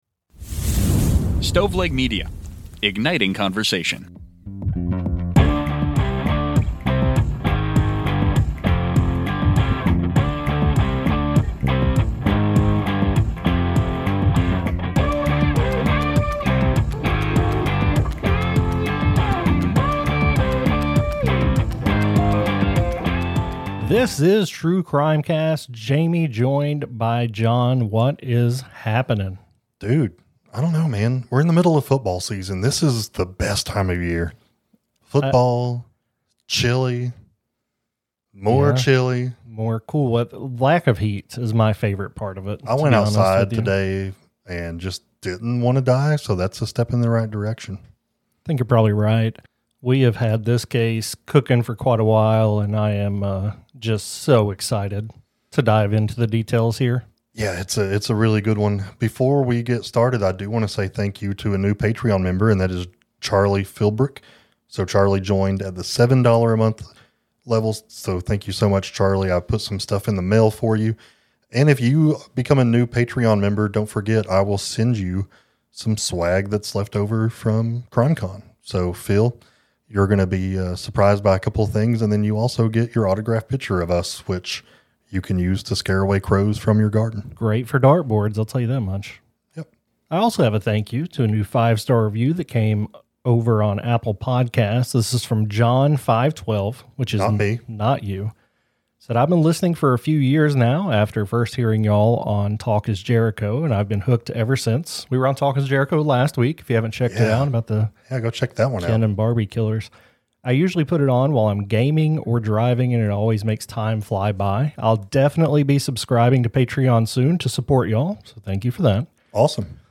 True Crime, Society & Culture, Documentary, Personal Journals